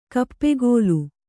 ♪ kappegōlu